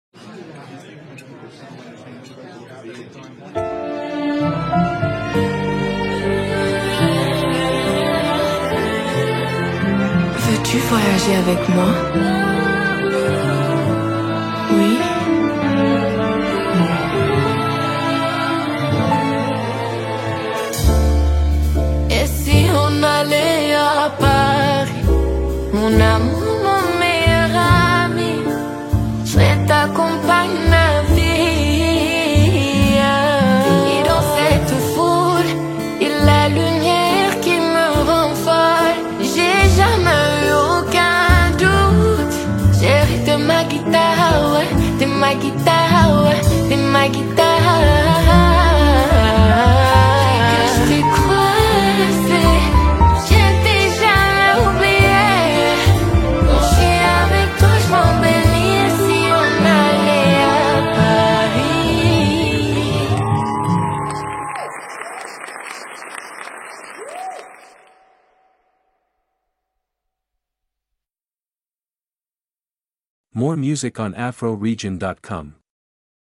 silky vocals elevating the production